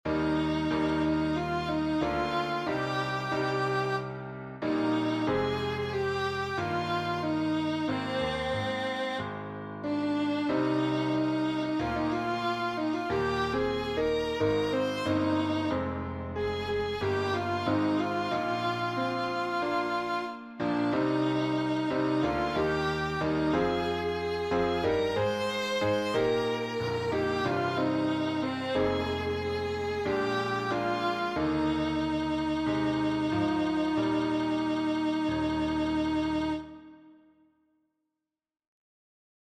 Accompaniment